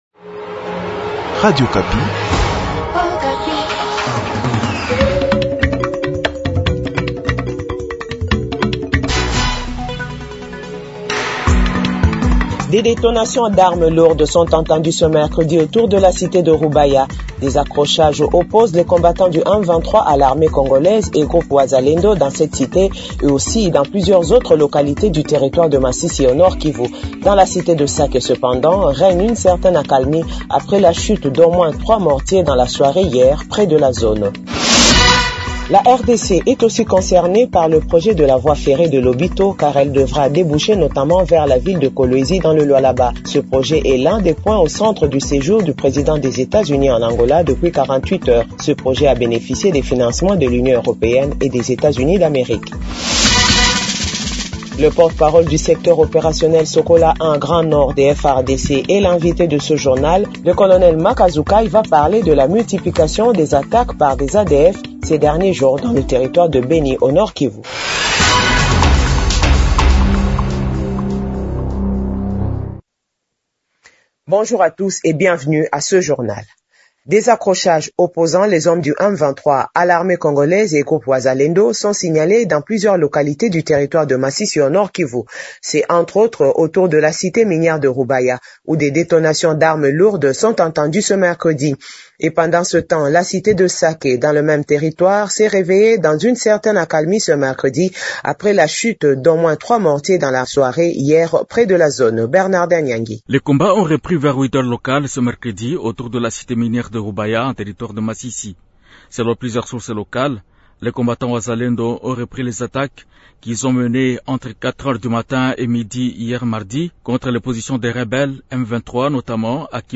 JOURNAL FRANÇAIS DE 15H00